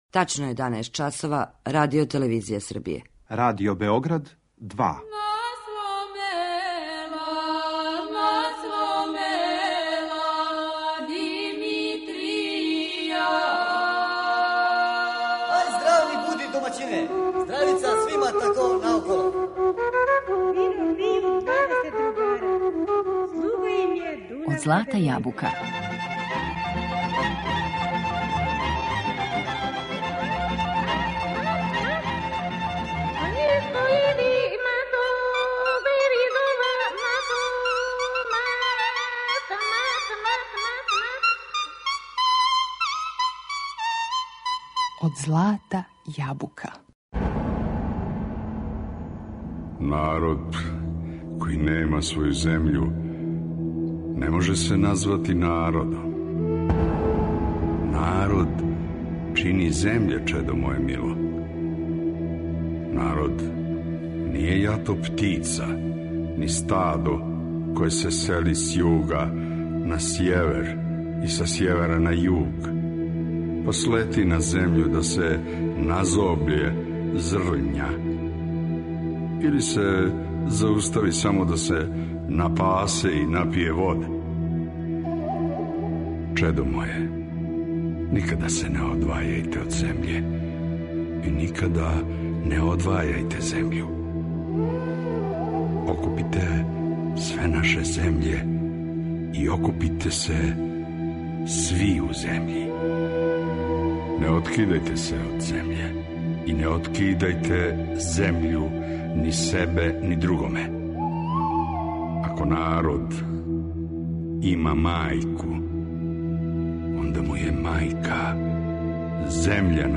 Снимак свечаног концерта Нациoналног ансамбла „Венац”
У емисији Од злата јабука слушамо снимак свечаног концерта Нациoналног ансамбла „Венац” са Косова и Метохије који је одржан у Народном позоришту у Београду 17. децембра 2019. године поводом обележавања 55 година од оснивања тог ансамбла.